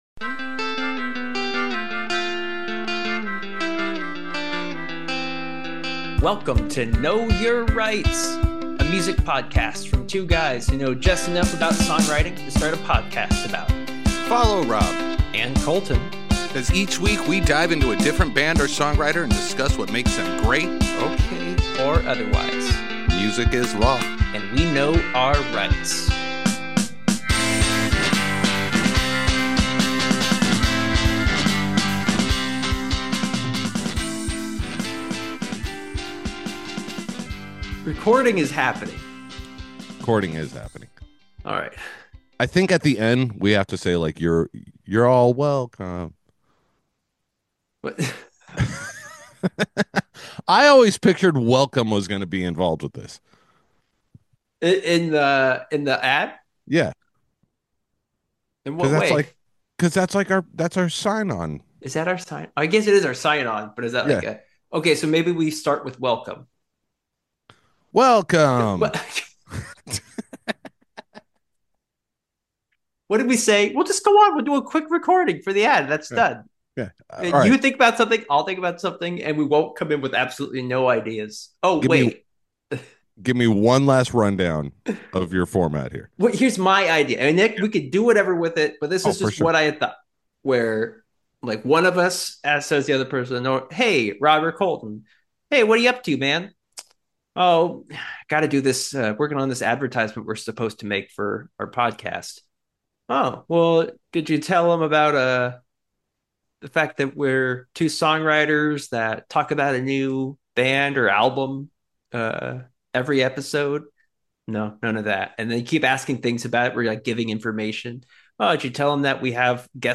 Two friends. Two microphones.